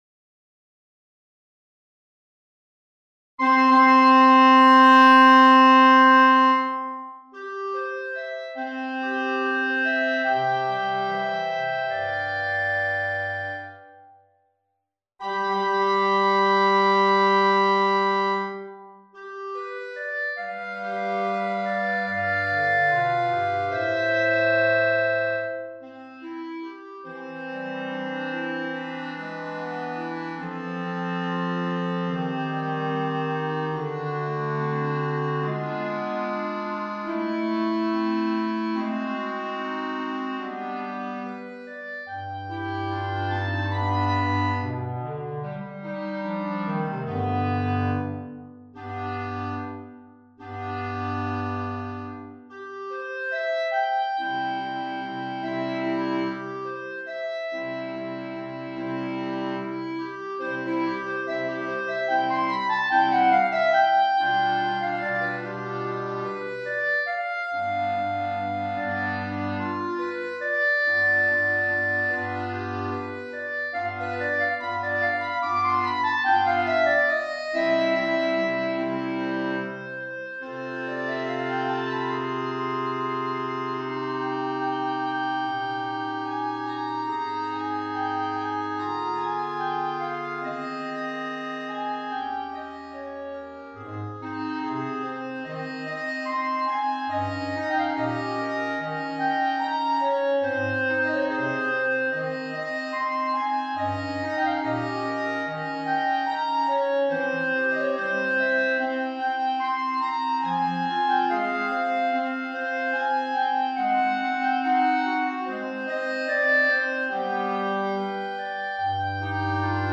elaborazione per Clarinetto in Do o Sib e Coro di Clarinetti